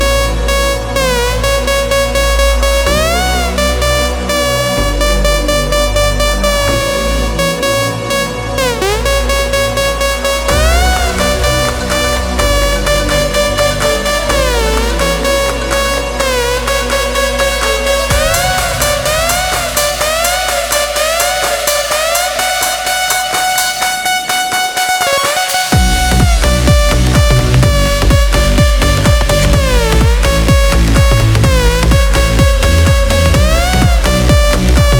2020-04-27 Жанр: Танцевальные Длительность